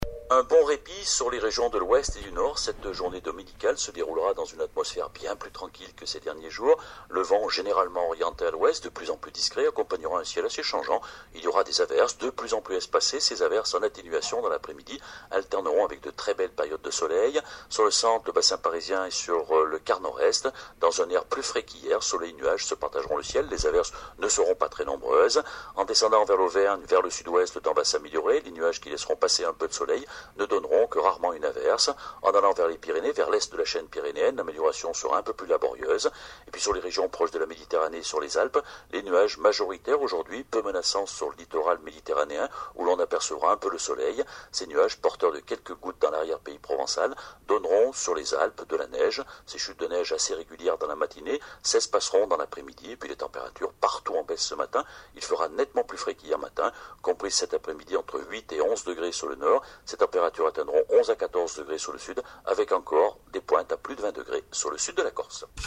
bulletin-mc3a9tc3a9o.mp3